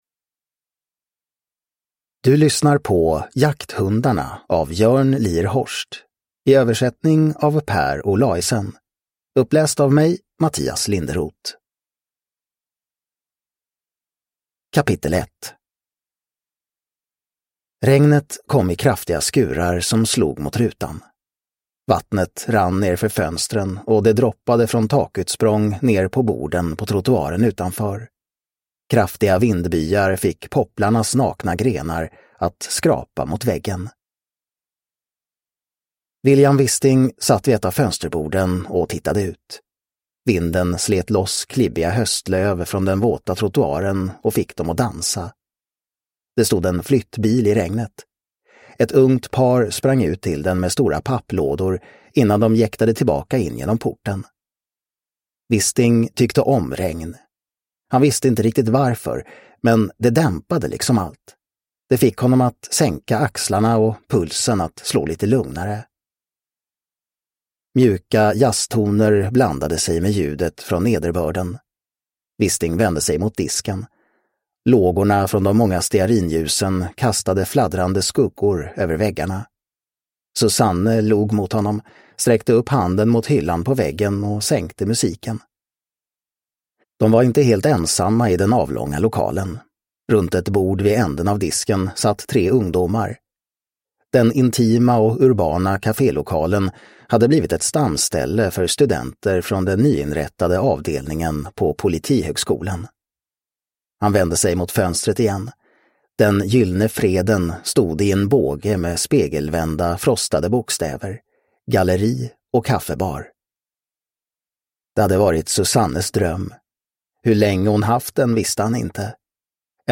Jakthundarna – Ljudbok – Laddas ner